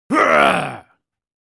Human Sounds / Sound Effects 25 Dec, 2024 Angry Man Shouting Sound Effect Read more & Download...
Angry-man-shouting-sound-effect.mp3